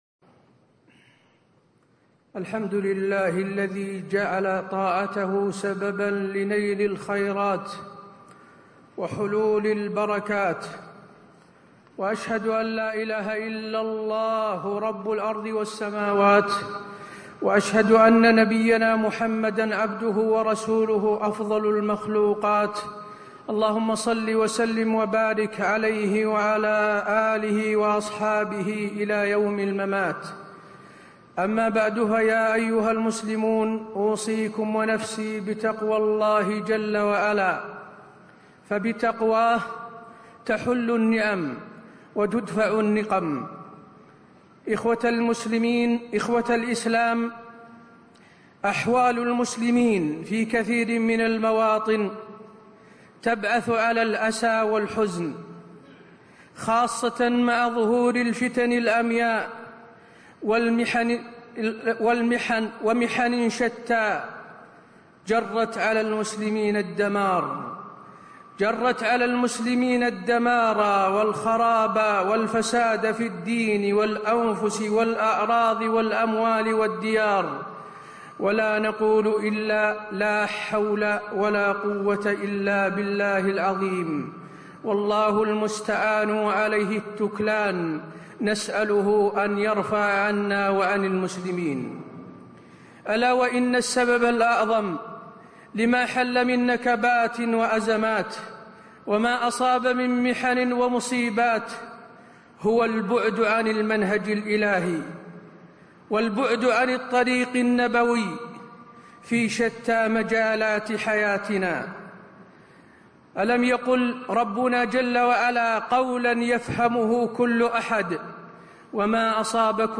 تاريخ النشر ٨ صفر ١٤٣٧ هـ المكان: المسجد النبوي الشيخ: فضيلة الشيخ د. حسين بن عبدالعزيز آل الشيخ فضيلة الشيخ د. حسين بن عبدالعزيز آل الشيخ موقف المسلم من الأحداث الجارية